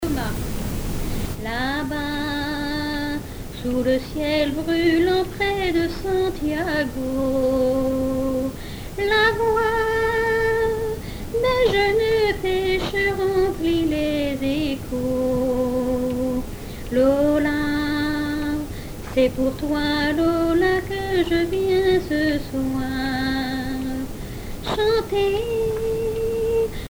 Epesses (Les)
Genre strophique
chansons de variété et traditionnelles
Pièce musicale inédite